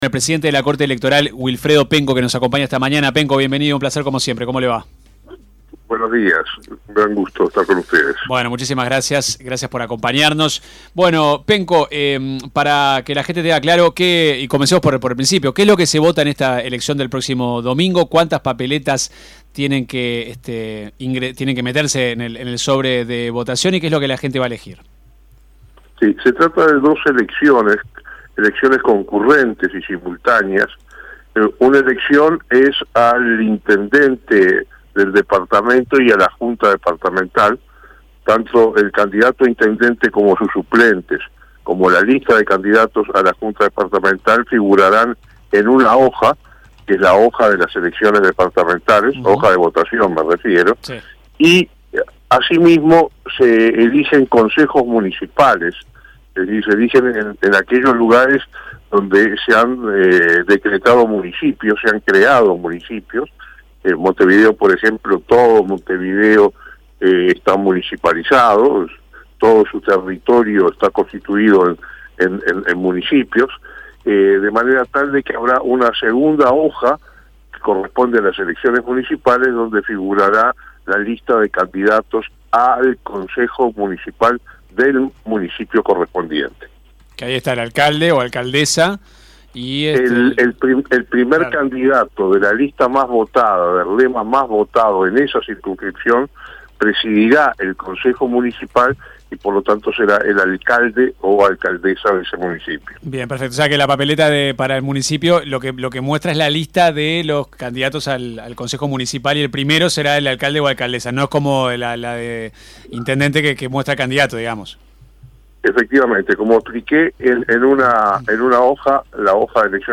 El presidente de la Corte Electoral, Wilfredo Penco en entrevista con 970 Noticias explicó como se desarrollarán las elecciones departamentales y municipales del próximo domingo 11 de mayo.